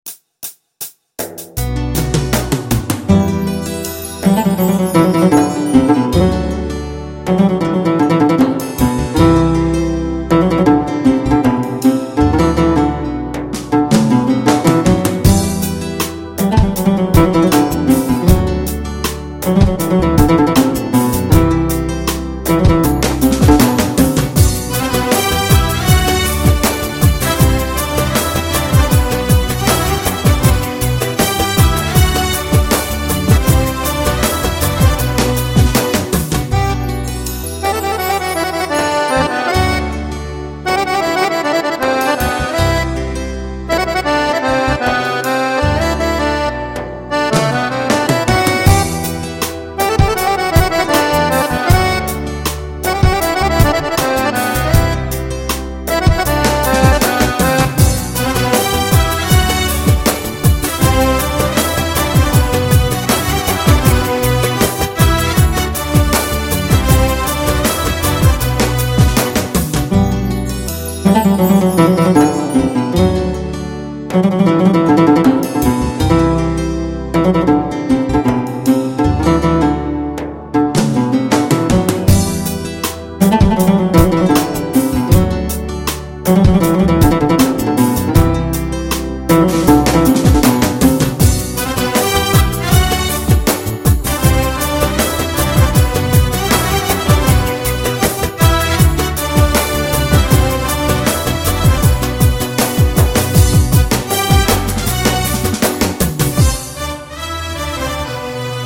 Roland Bk5 Or - ( Slow ) - Demo